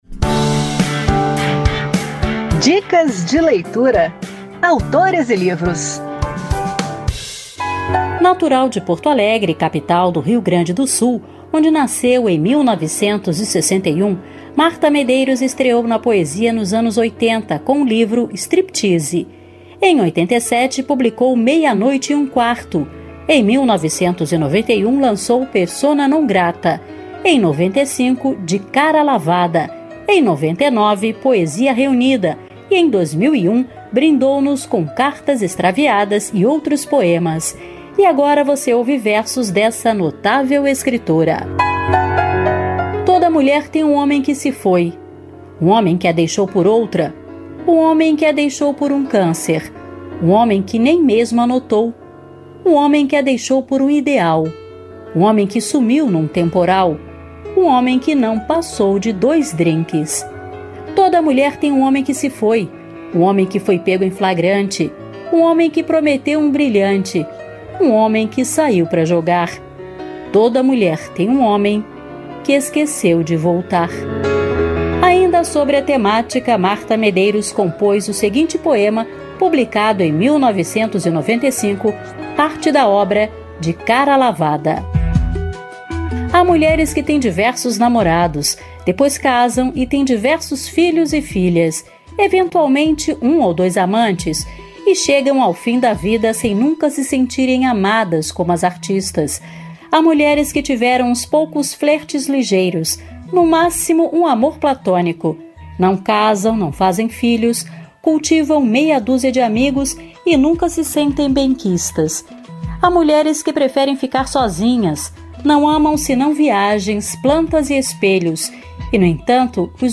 Em um dos livros citados, “De cara lavada”, Marta Medeiros nos brinda com poemas dedicados às mulheres. Confira as dicas e ouça dois poemas da escritora.